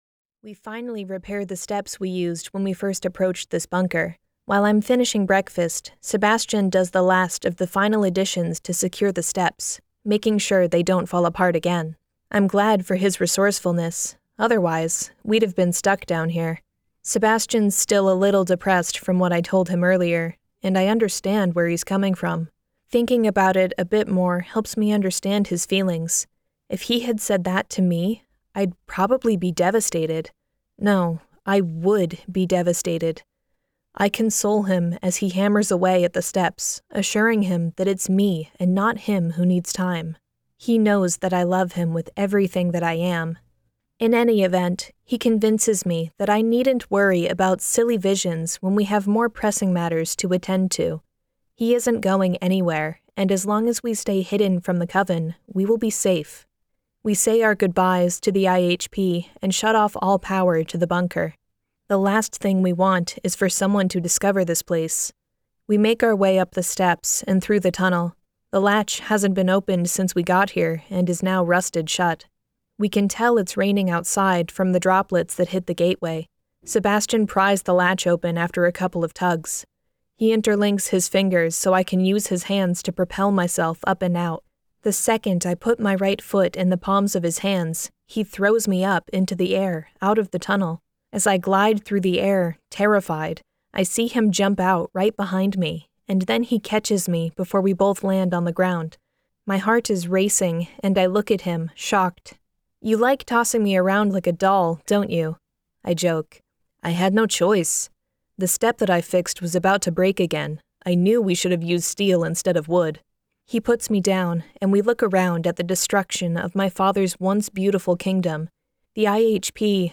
Dragoius Reborn Audiobook | (The Blood’s Passion Saga Book 2) 1st Edition